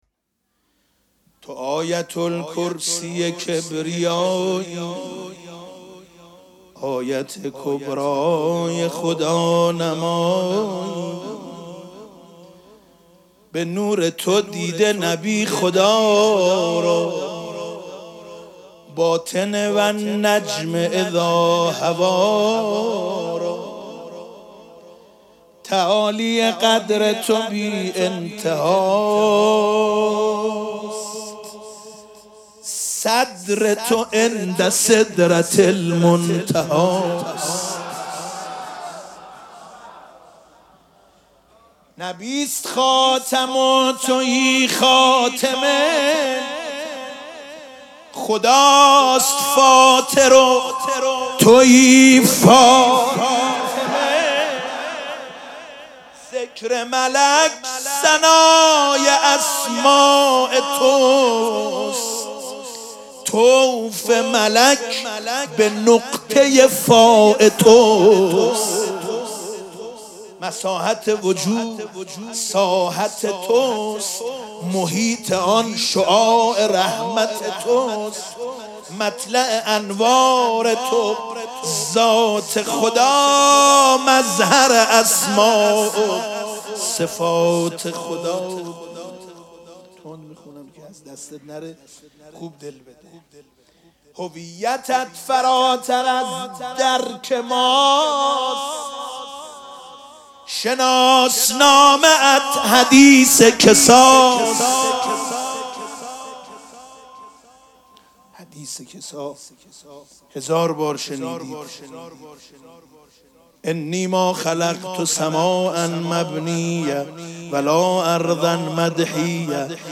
مراسم جشن ولادت حضرت زهرا سلام الله علیها
حسینیه ریحانه الحسین سلام الله علیها
مدح